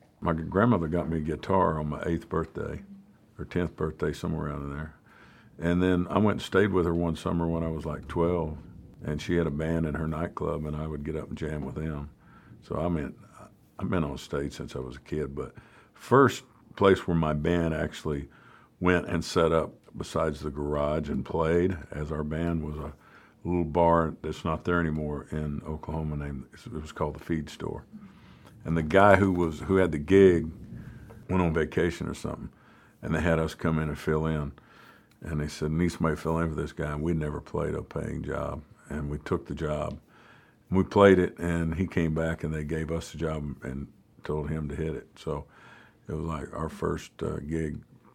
Toby Keith talks about his first times on stage.